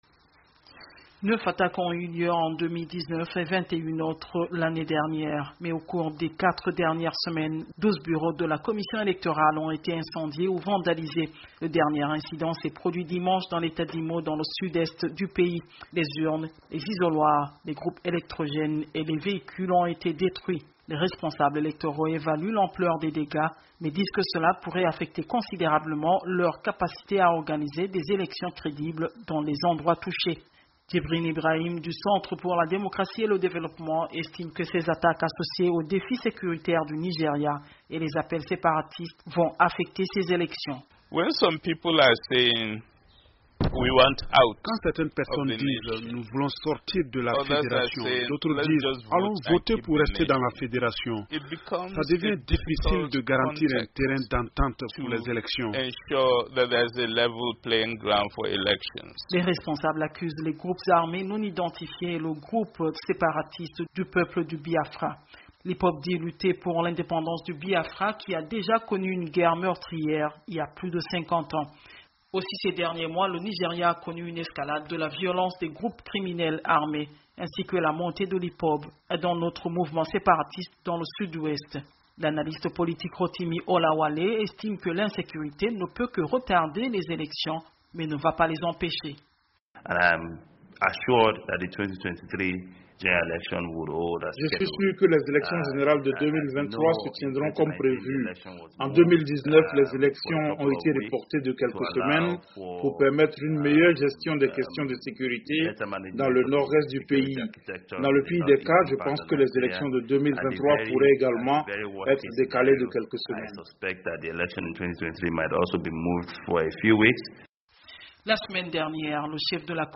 Au Nigeria les observateurs politiques se disent préoccupés par les nombreuses attaques contre des installations de la Commission électorale nationale indépendante. Les responsables de cette commission disent avoir enregistré au moins 42 attaques depuis les dernieres elections en 2019. Le reportage